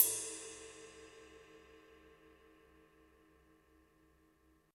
Index of /90_sSampleCDs/Roland L-CD701/CYM_Rides 1/CYM_Ride menu
CYM TS RID03.wav